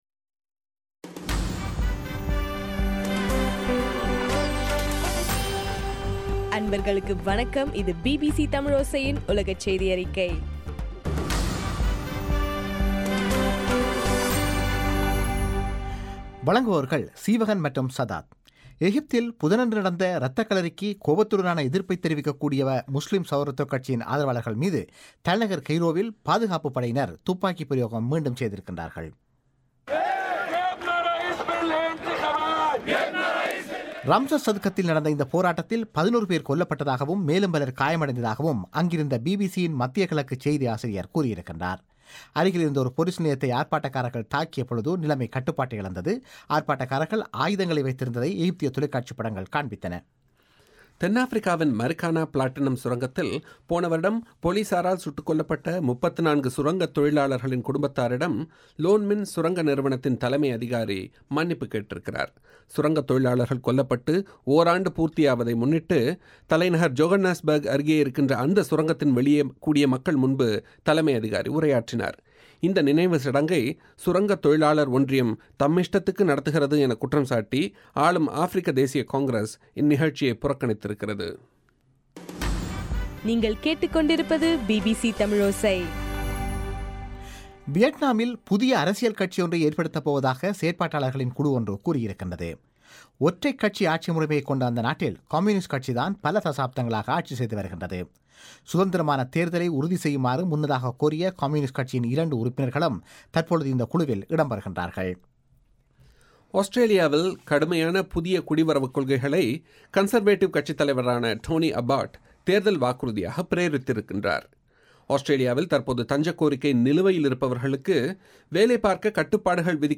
இலங்கையின் சக்தி எஃப்எம் வானொலியில் ஒலிபரப்பான பிபிசி தமிழோசையின் உலகச் செய்தியறிக்கை